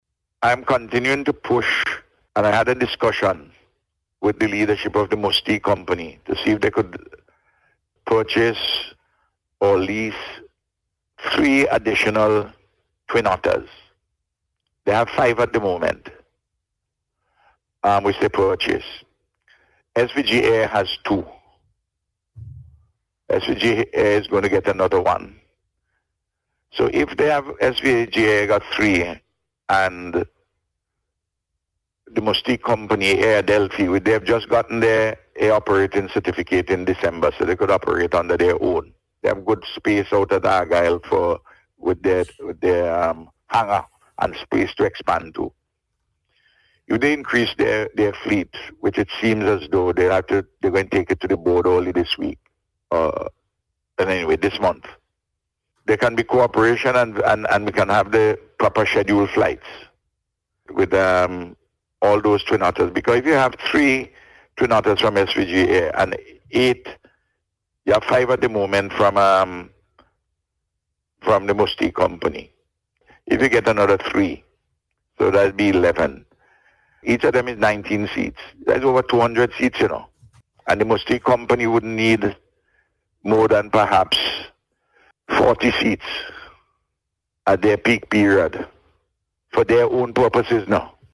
The Prime Minister said on Radio on Sunday that he is continuing to look at ways to bring a solution to the issue of Regional Air Transport